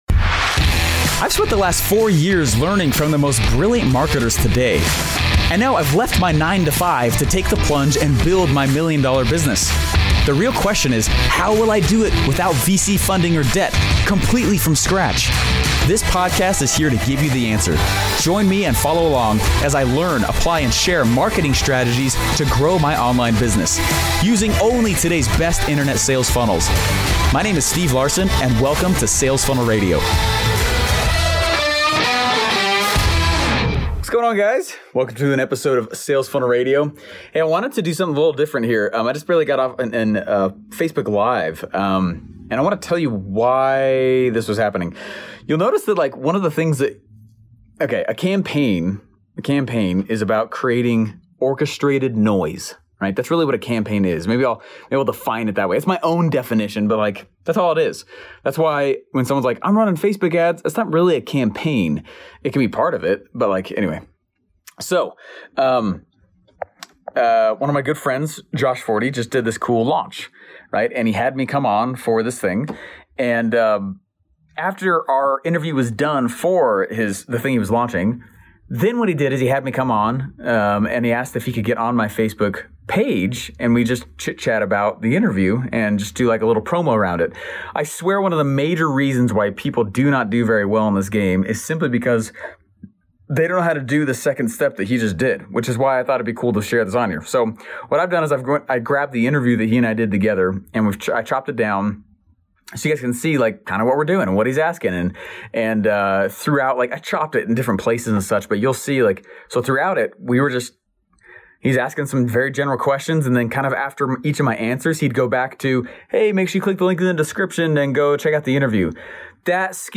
...which is why I thought it'd be cool to share the interview with you…